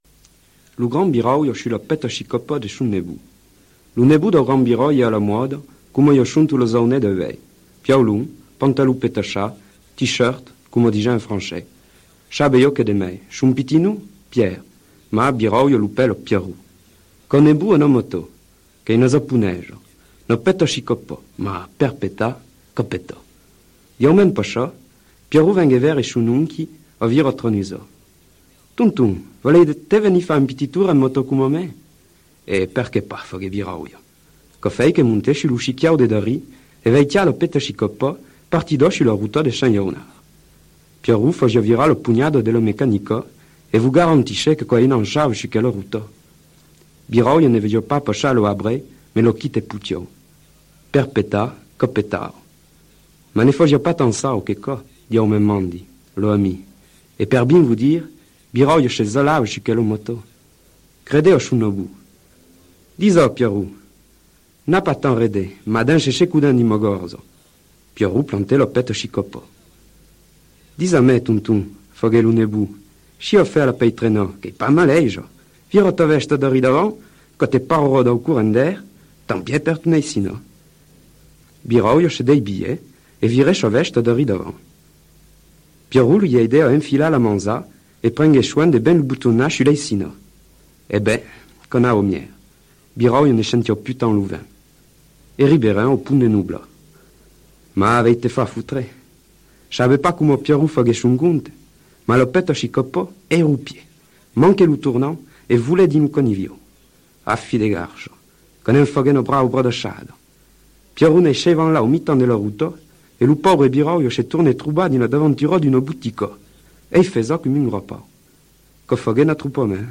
(parler de la Montagne Limousine)